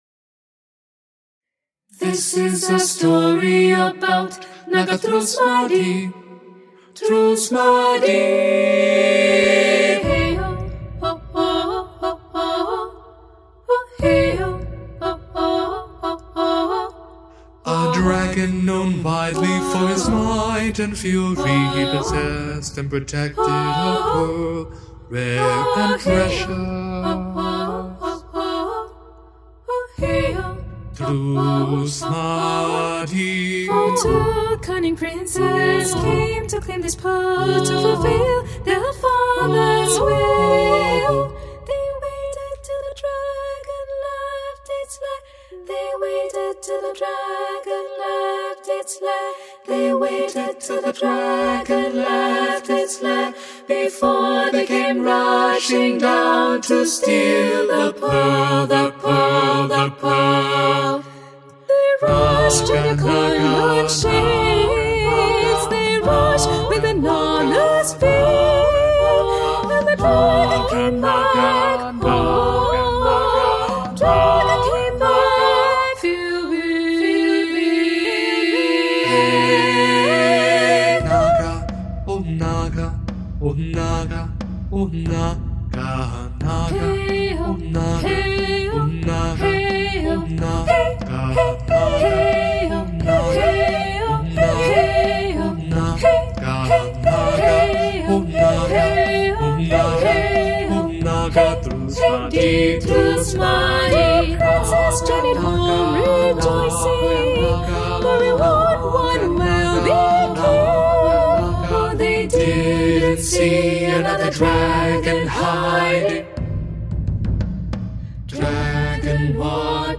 SAB, instr. percussion
a fiery composition for SAB choir and percussion